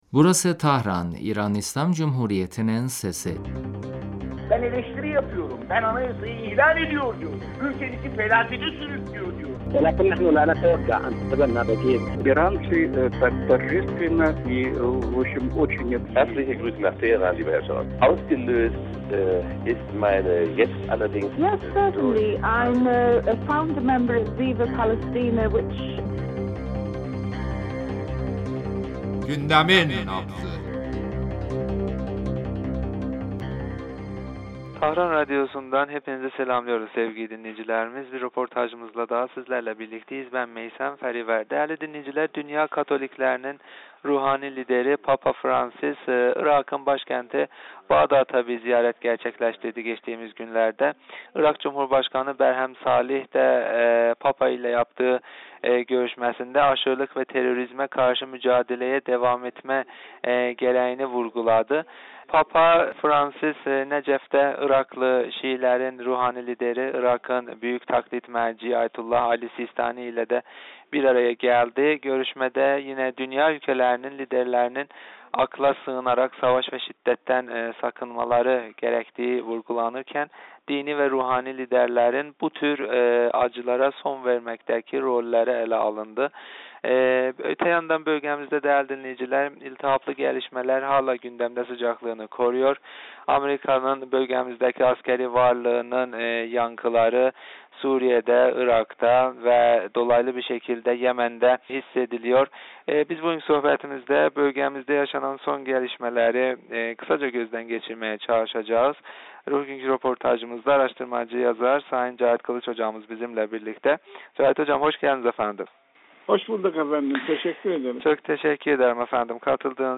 radyomuza verdiği demecinde bölge gelişmeleri kapsamında son yaşananları değerlendirdi...